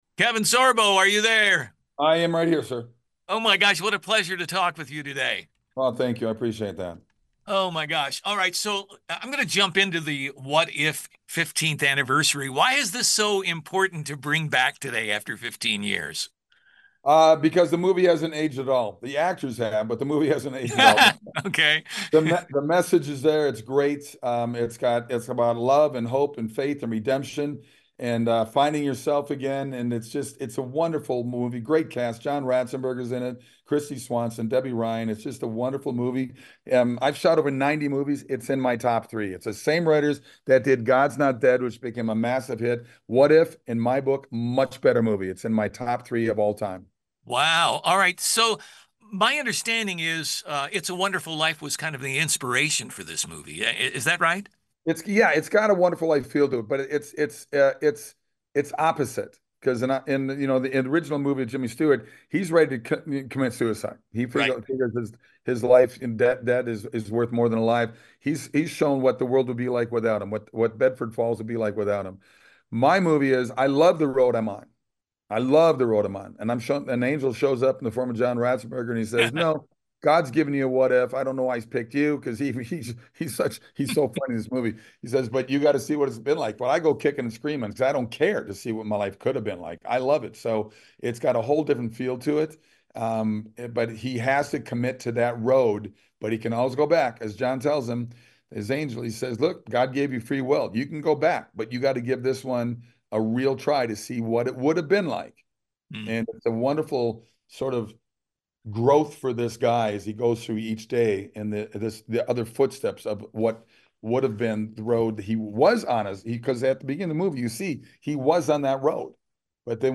KEVIN SORBO – Talks His Movie, “What If”.  Full Interview.